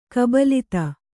♪ kabalita